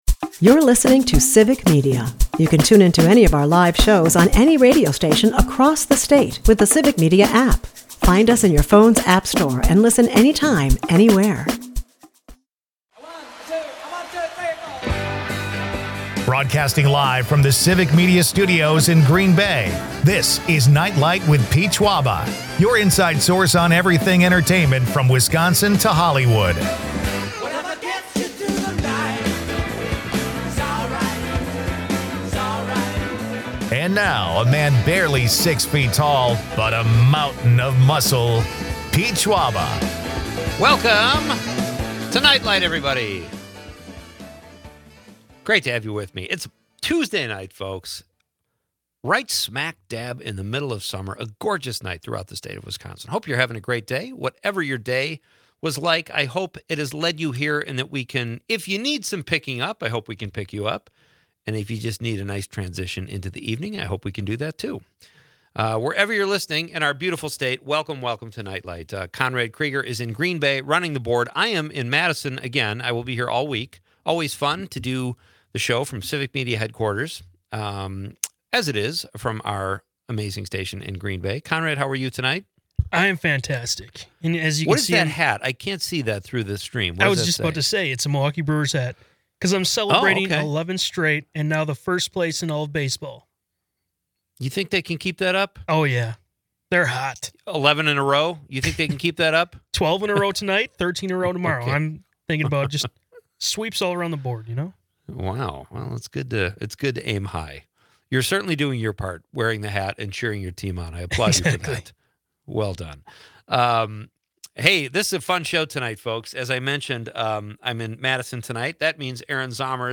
The hosts discuss summer blockbusters like Superman and Jurassic World, weighing in on their appeal. Among the laughter and film talk, they remember Ozzy Osbourne's impact following his passing. A playful exchange on personal quirks and movie-going experiences rounds out the evening.